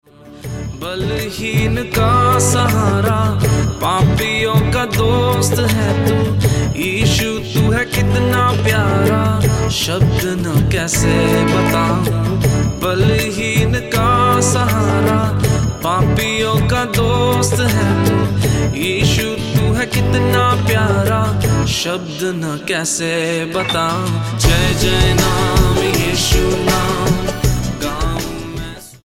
Style: World Approach: Praise & Worship